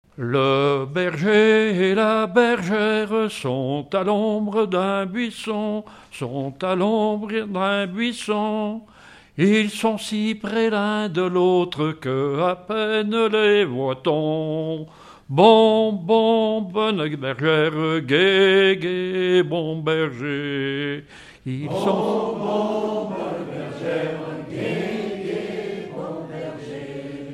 Mémoires et Patrimoines vivants - RaddO est une base de données d'archives iconographiques et sonores.
Pièce musicale inédite